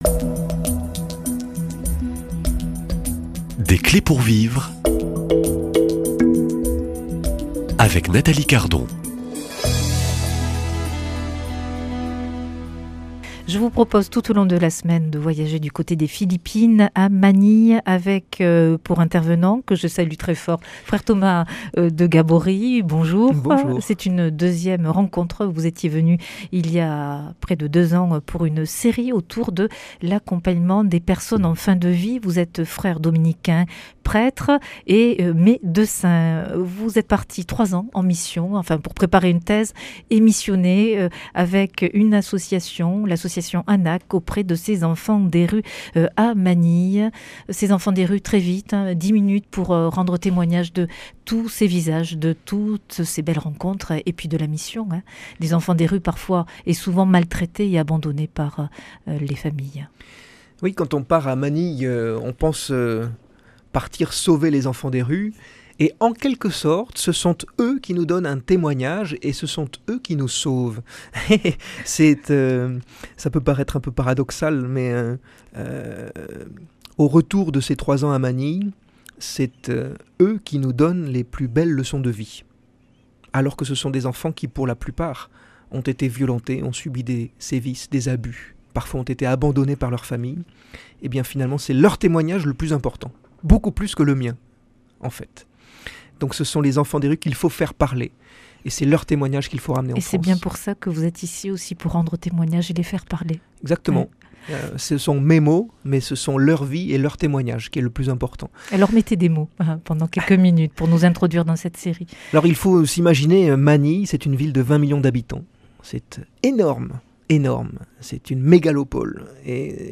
Dominicain et prêtre